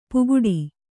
♪ puguḍi